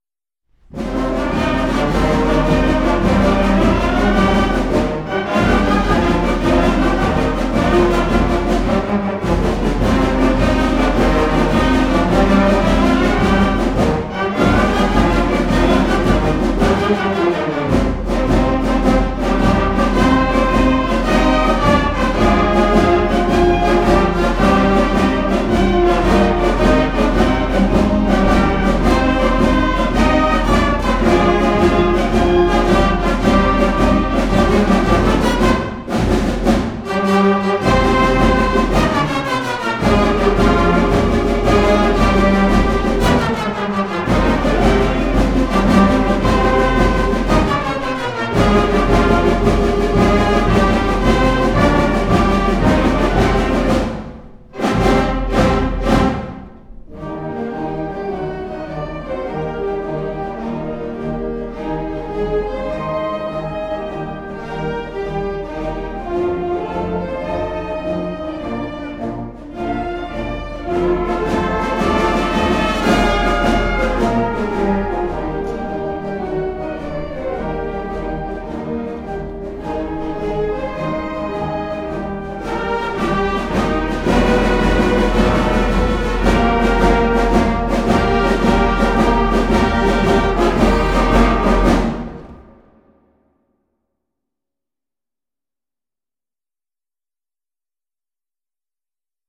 very powerful 6/8 march
Flute
Clarinet 1, 2
Bass Clarinet
Alto Sax
Tenor Sax
Trumpet 1, 2
F Horn
Trombone
Tuba
Percussion